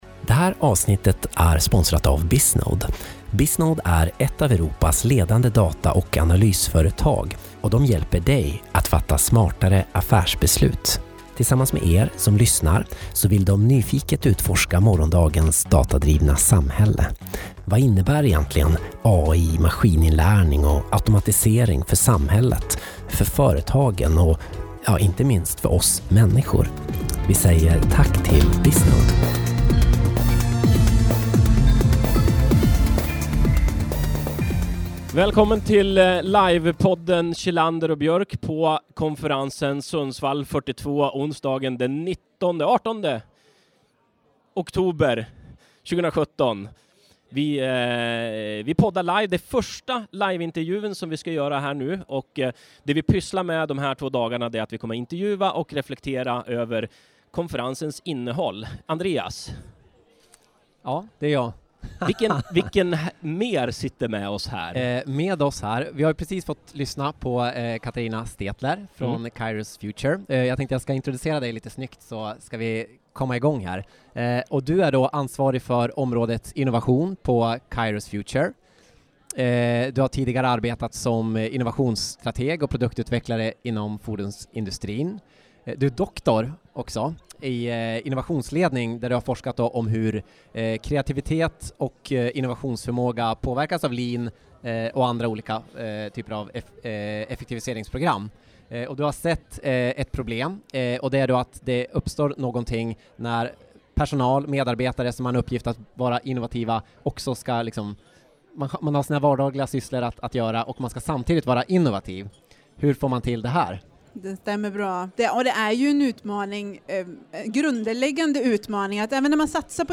Sundsvall 42 Det här avsnittet ingår i vår serie från livepoddningen under Sundsvall 42.